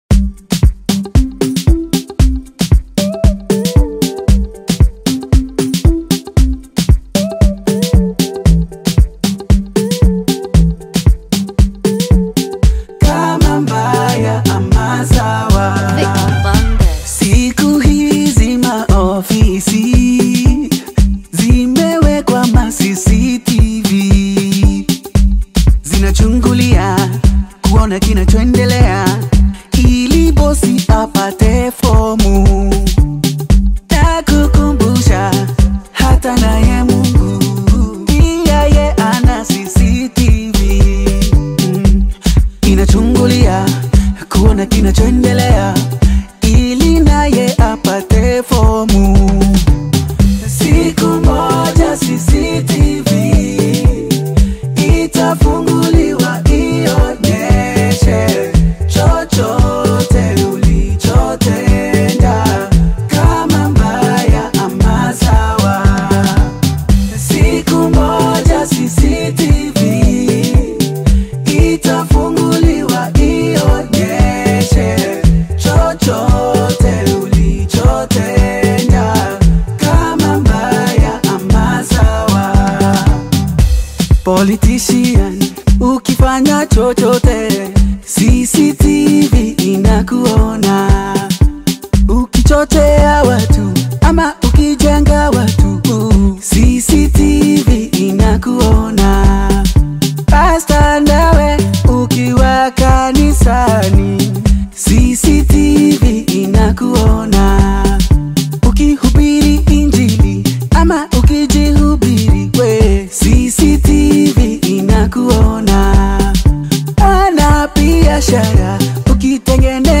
Kenyan Gospel music